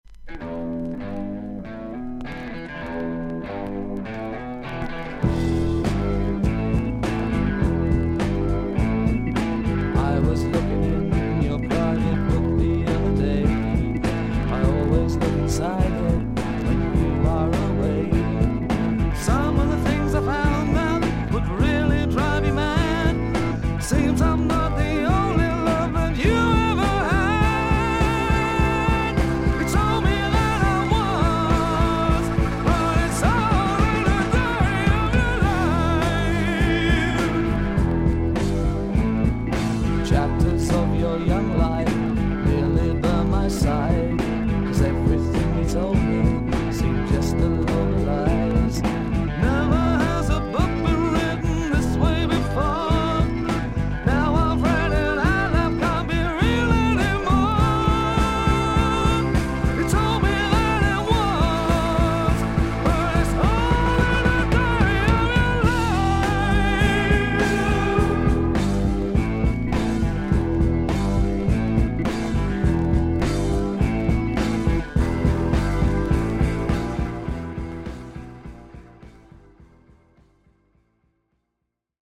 少々軽いパチノイズの箇所あり。少々サーフィス・ノイズあり。クリアな音です。
ブルース・ロック・バンド。